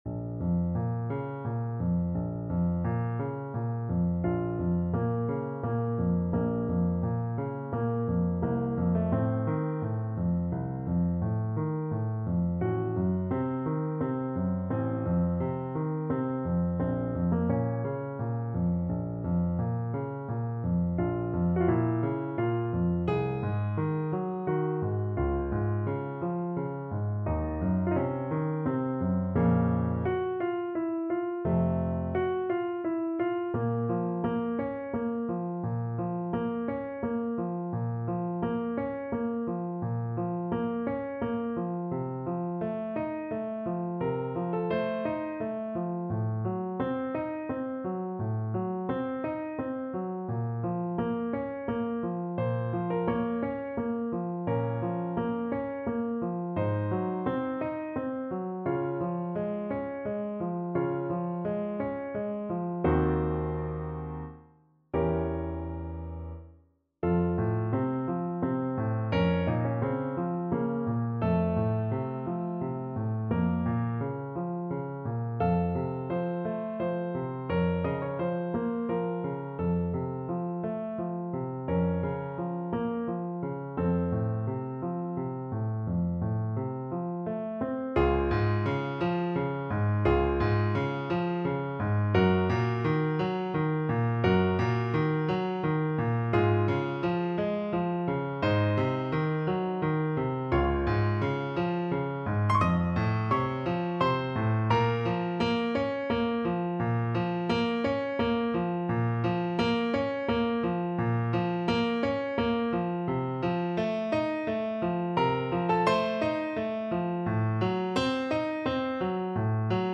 6/8 (View more 6/8 Music)
Bb minor (Sounding Pitch) (View more Bb minor Music for Bassoon )
Larghetto = c. 86
Classical (View more Classical Bassoon Music)